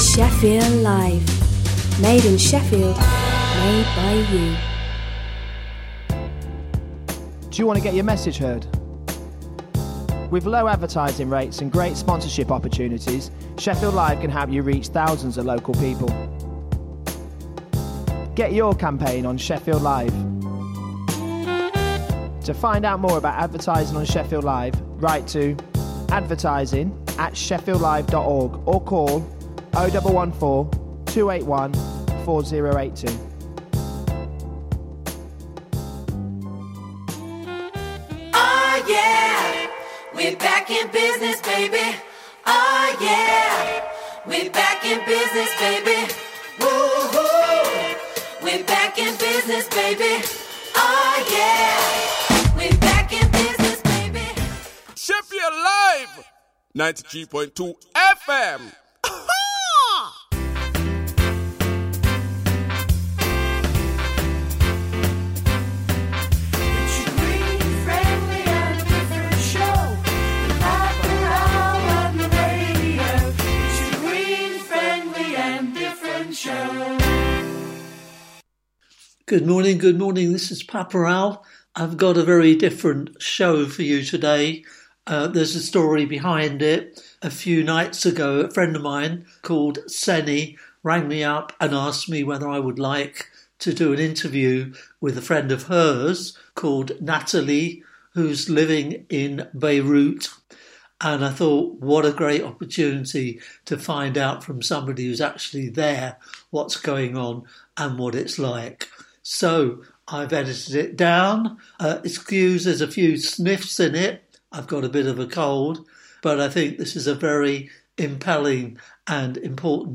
Information about radical environmental projects, innovative regeneration activities, views on the city’s development and off-the-wall cultural projects with a wide range of music from across the world.